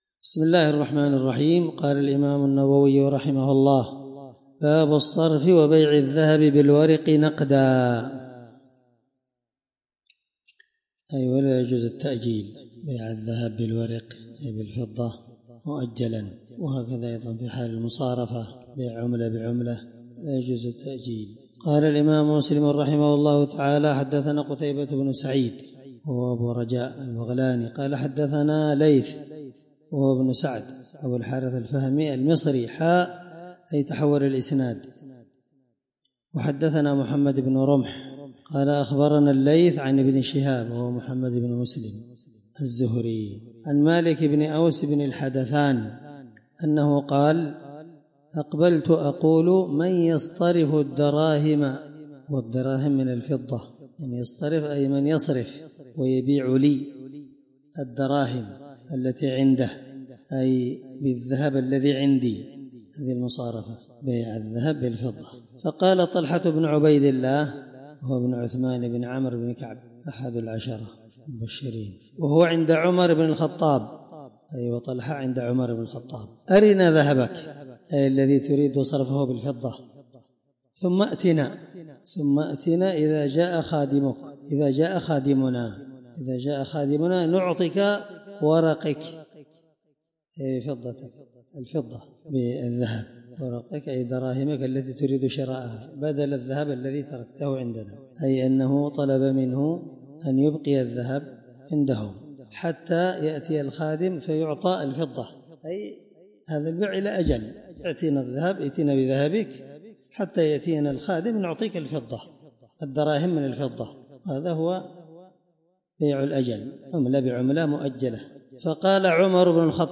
الدرس17من شرح كتاب المساقاة حديث رقم(1786-1588) من صحيح مسلم